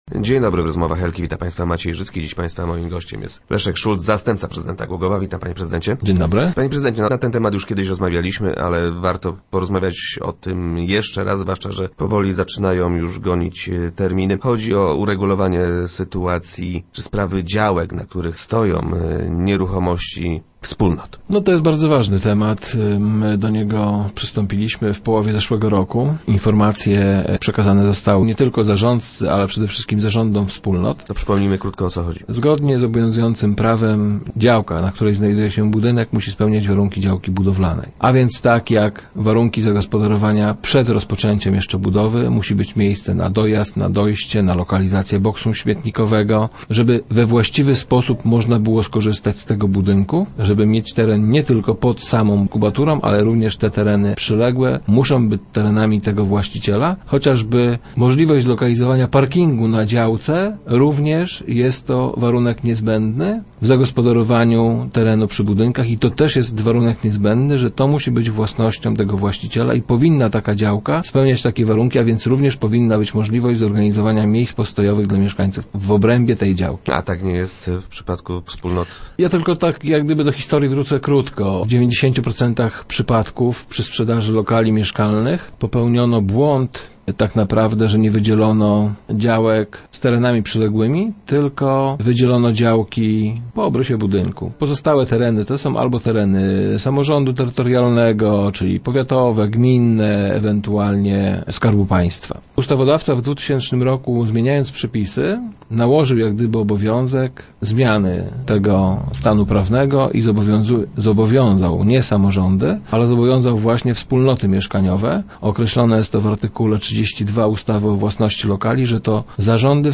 - Na działkach musi być miejsce na dojazd, na boksy śmietnikowe, na chodniki i parkingi. Tak by we właściwy sposób można było korzystać z tego budynku. Nie tylko działka pod samą kubaturą, ale też tereny przyległe, muszą mieć tego samego właściciela - tłumaczył na radiowej antenie wiceprezydent Szulc.